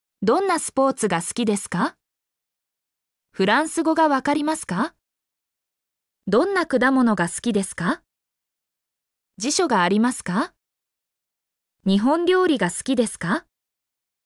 mp3-output-ttsfreedotcom-6_wMzSApUY.mp3